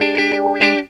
GTR 48 EM.wav